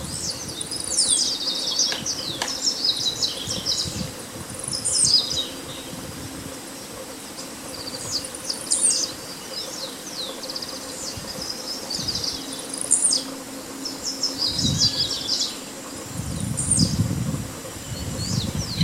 Blue-black Grassquit (Volatinia jacarina)
Class: Aves
Location or protected area: Reserva Natural del Pilar
Condition: Wild
Certainty: Recorded vocal